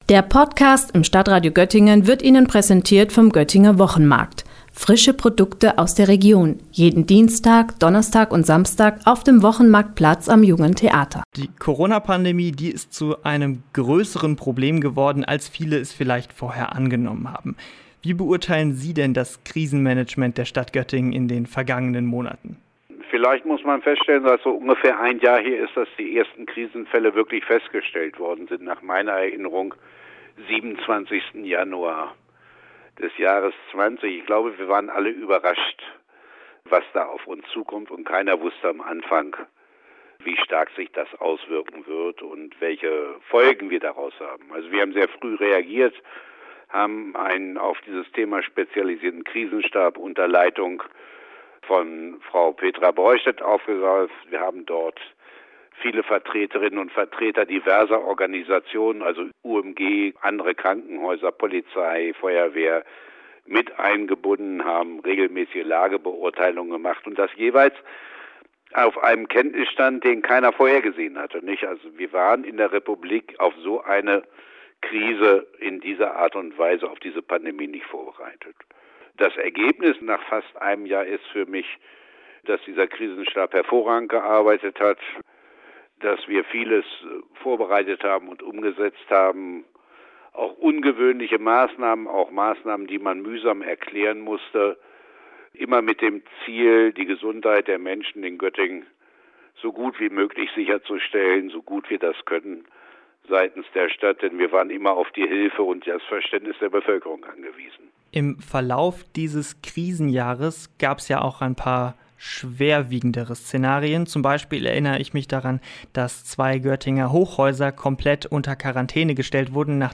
Göttingens Oberbürgermeister Rolf-Georg Köhler im Neujahrsinterview